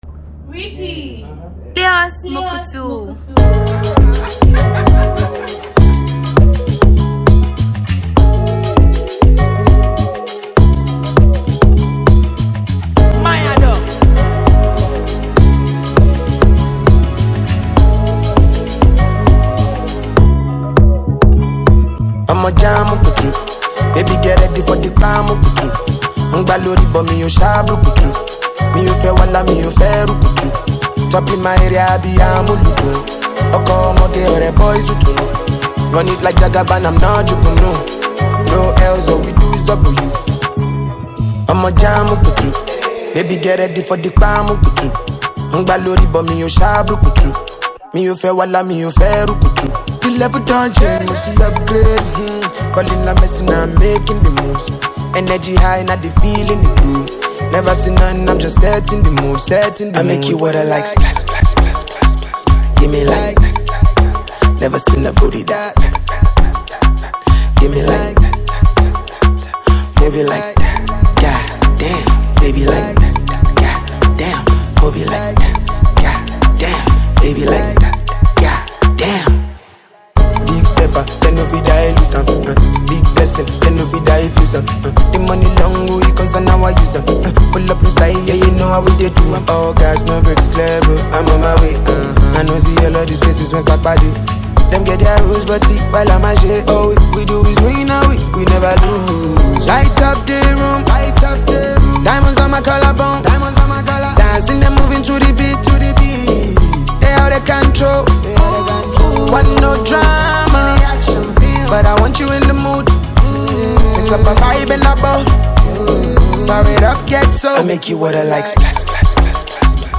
is an uptempo track
Genre: Afrobeat